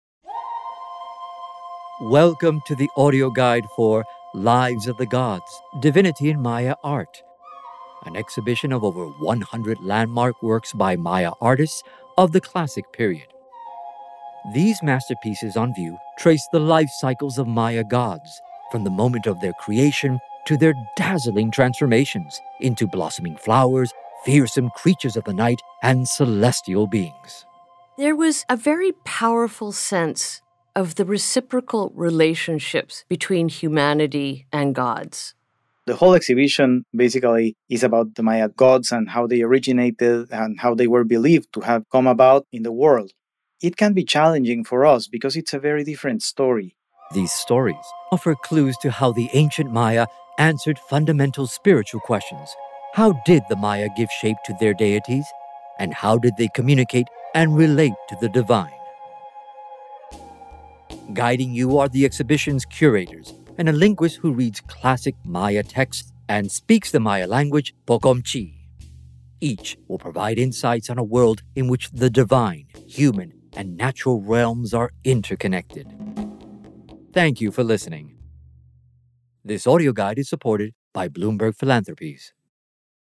Listen to exhibition curators discuss a who’s who of Maya gods, hear Classic Mayan inscriptions read aloud, and learn stories from speakers of Poqomchi’, one of many Mayan languages in use today.